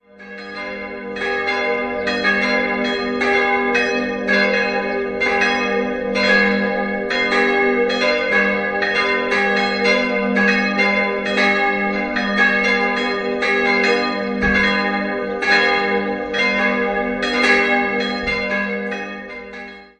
Der Unterbau des Kirchturmes der Pfarrkirche St. Stephan stammt wohl noch aus dem 15. Jahrhundert, während das Langhaus im 17. Jahrhundert neu errichtet wurde. Eine Innenansicht liegt derzeit nicht vor. 3-stimmiges As-Dur-Geläute: as'-c''-es'' Das Geläut, bestehend aus der Stephanus-, der Marien- und der kleinen Josefsglocke, wurde im Jahr 1950 von der Gießerei Czudnochowsky in Erding gegossen.